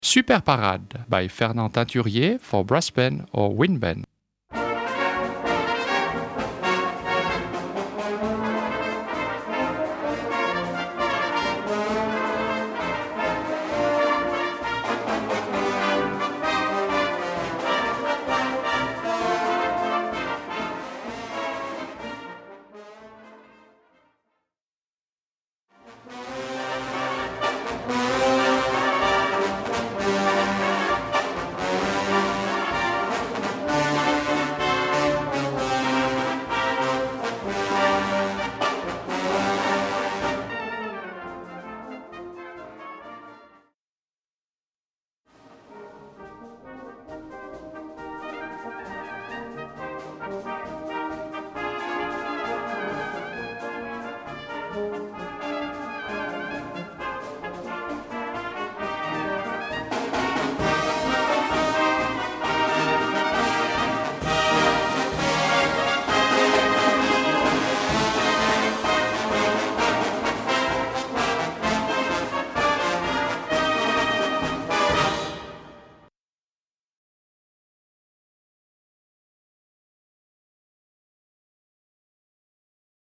Disponible en version Brass Band et Harmonie
Brass Band
Wind Band (harmonie)
Marches